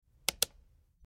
Sus Téléchargement d'Effet Sonore
The Sus sound button is a popular audio clip perfect for your soundboard, content creation, and entertainment.